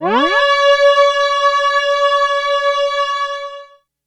Gliss 01.wav